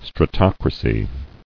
[stra·toc·ra·cy]